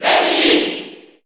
Lemmy_Cheer_Japanese_SSB4_SSBU.ogg